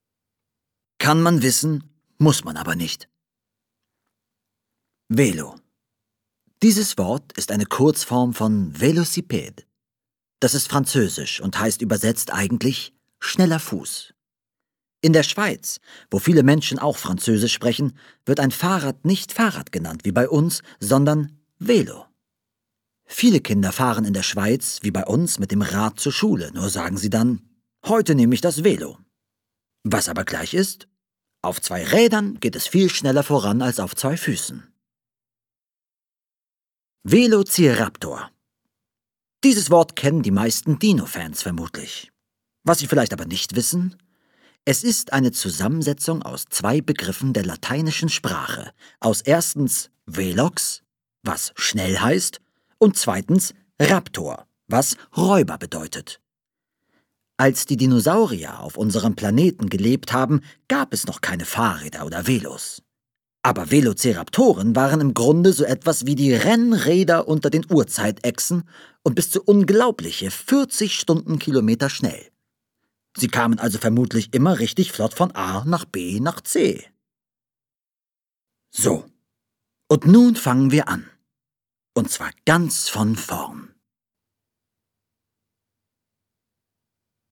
. Ungekürzt.